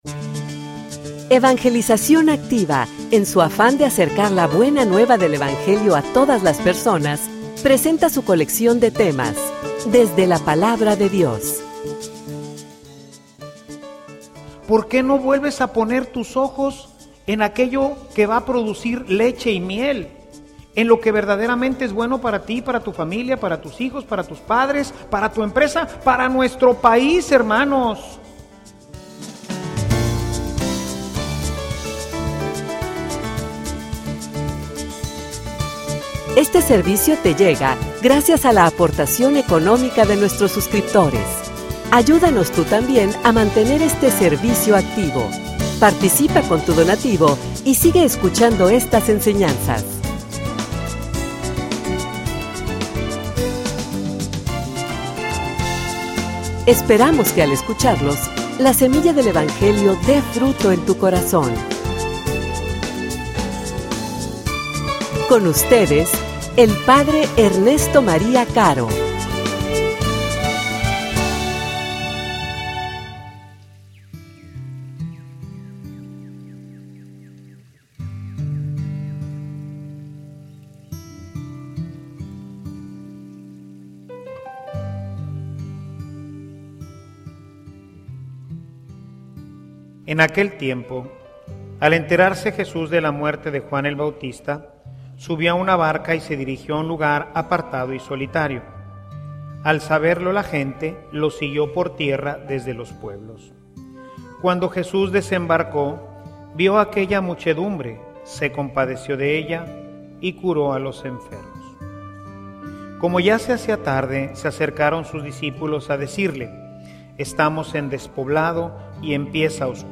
homilia_Por_que_gastar_en_lo_que_no_vale_la_pena.mp3